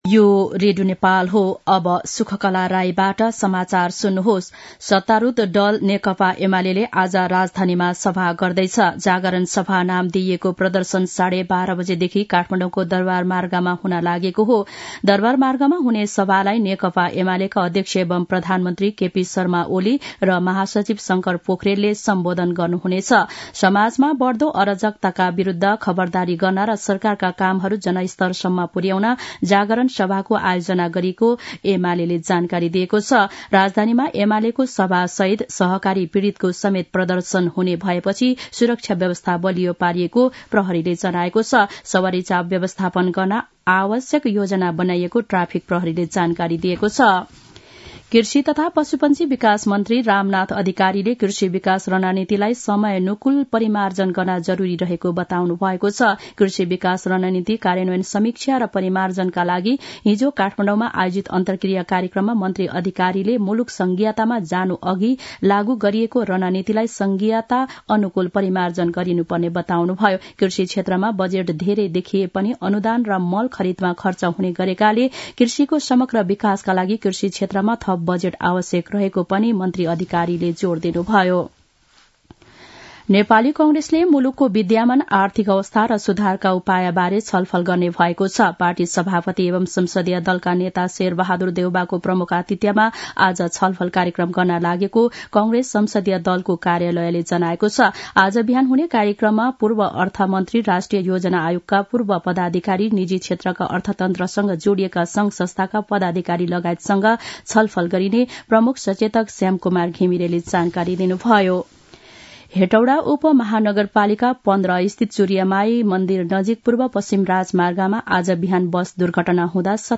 मध्यान्ह १२ बजेको नेपाली समाचार : ८ मंसिर , २०८१
12-am-nepali-news-1-6.mp3